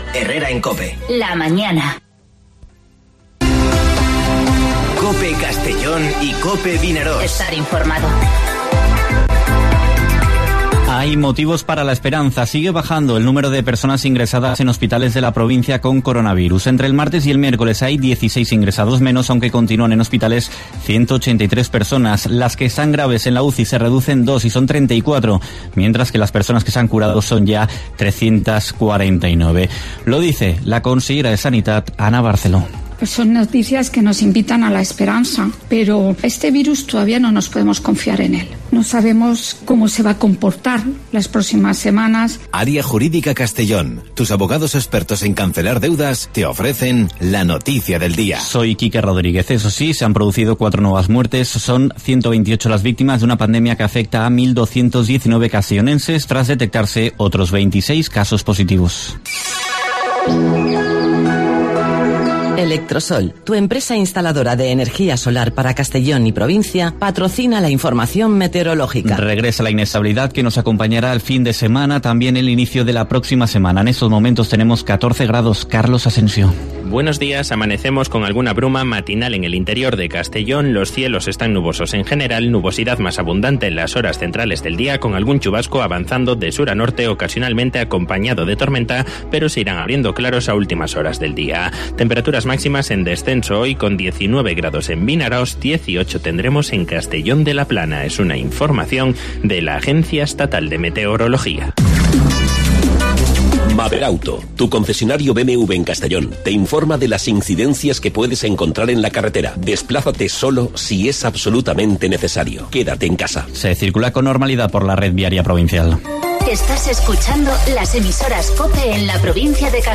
Informativo Herrera en COPE en la provincia de Castellón (17/04/2020)